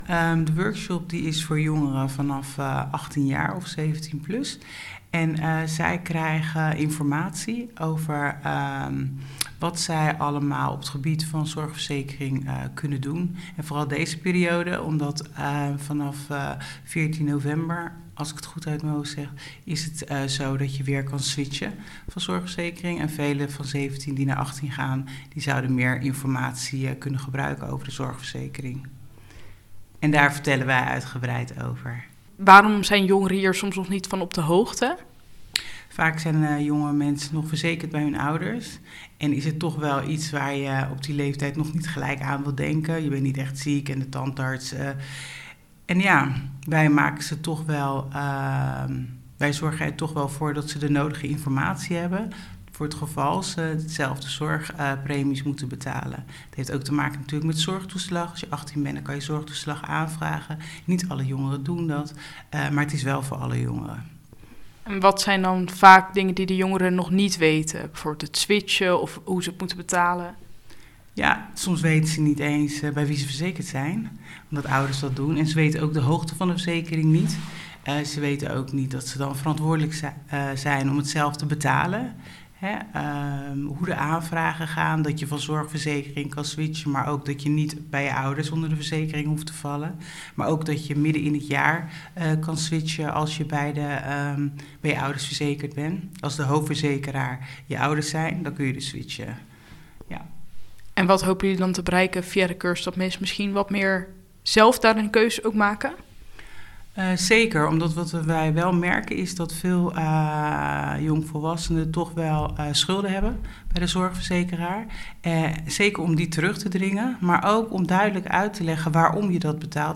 Interview Leiden Nieuws